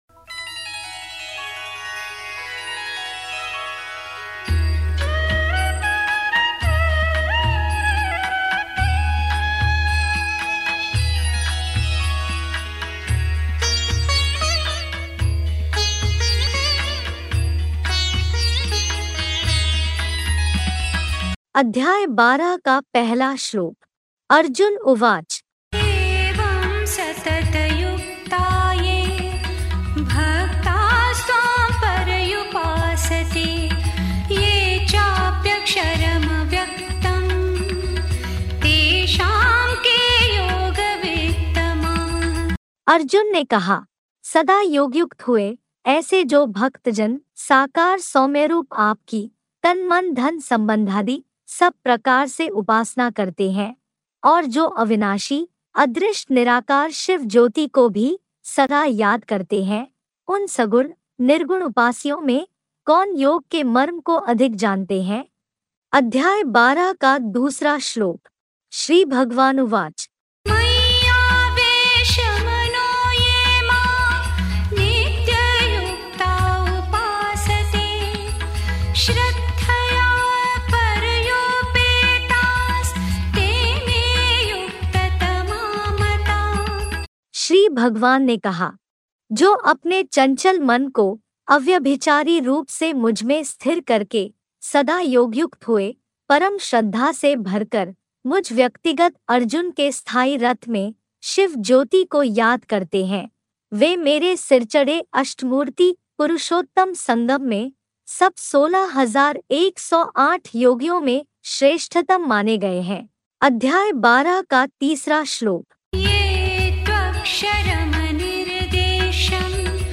अध्याय -12 श्लोक उच्चारण